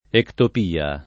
ectopia [ ektop & a ]